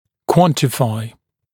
[‘kwɔntɪfaɪ][‘куонтифай]выражать в количественном отношении, определять количество, измерять